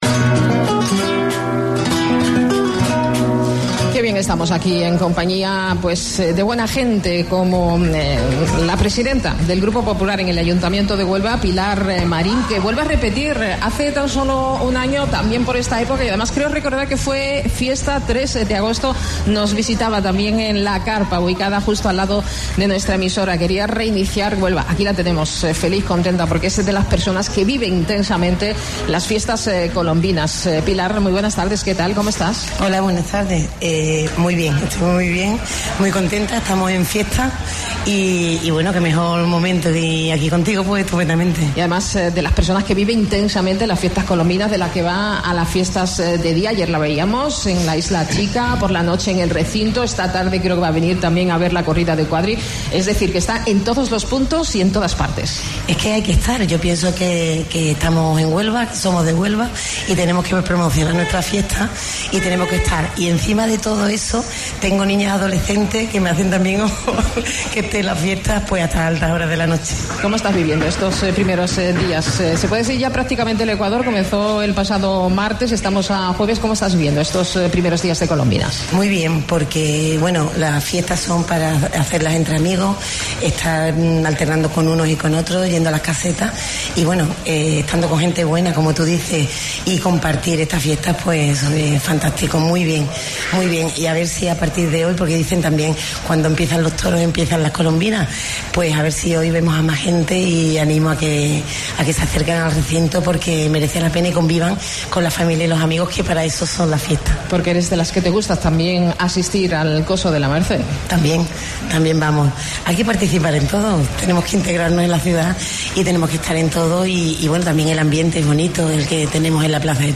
AUDIO: Presidenta del Grupo Popular en el Ayuntamiento, en la Carpa "DESCUBRE HUELVA" de Colombinas